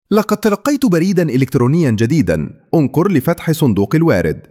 Narration with text and audio synced